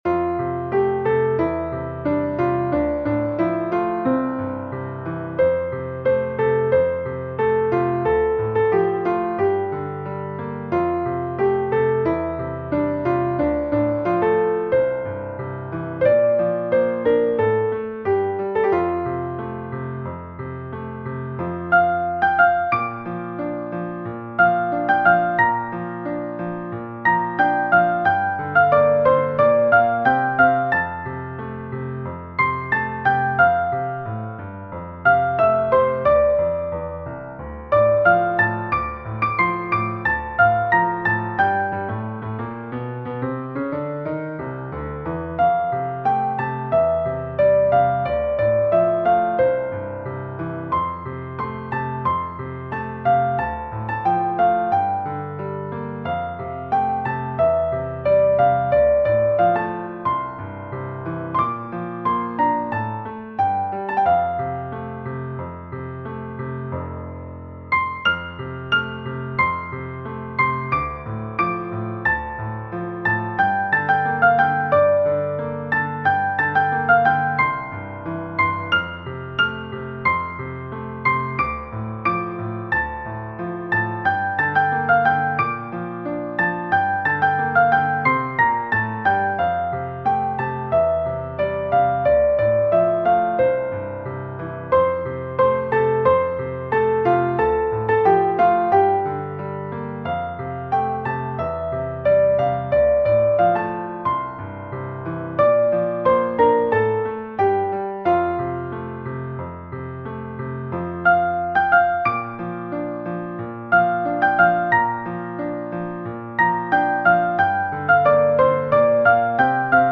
Thể loại 🎹 Piano, Nhạc Xuân, 🎹 Piano xuân
Key: F major, D minor (F, Dm)